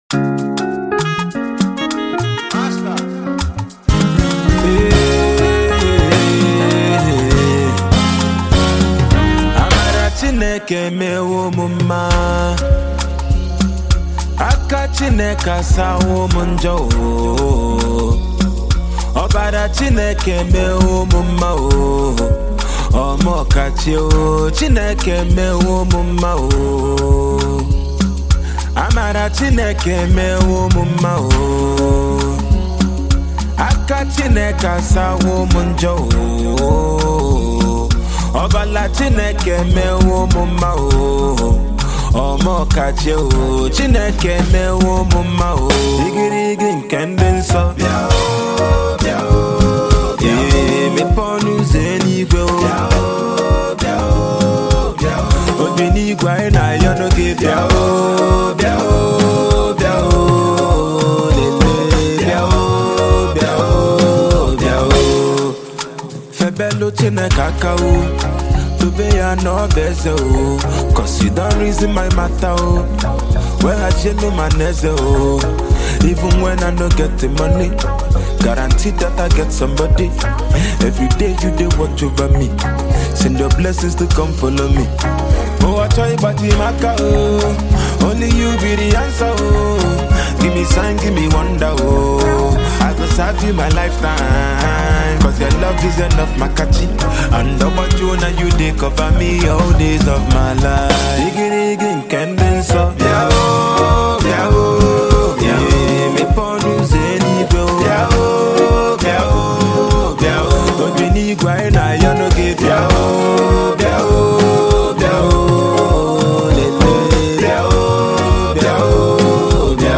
Nigerian indigenous rapper, songwriter, singer
classic Afrobeat/ Gospel-Pop song